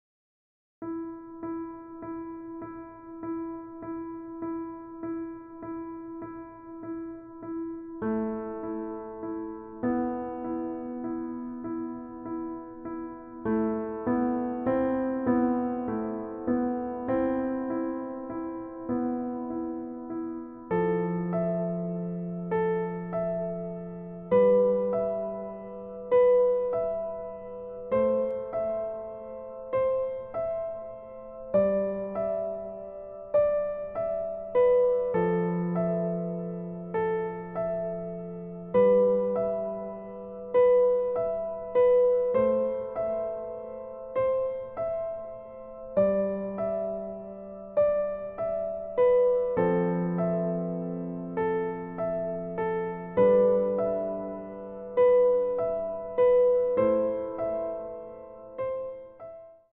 EASY Piano Tutorial